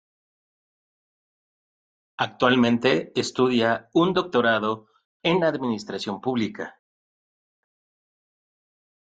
Pronunciato come (IPA)
/doɡtoˈɾado/